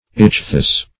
Ichthus synonyms, pronunciation, spelling and more from Free Dictionary.
Search Result for " ichthus" : The Collaborative International Dictionary of English v.0.48: Ichthus \Ich"thus\ ([i^]k"th[u^]s), n. [Gr.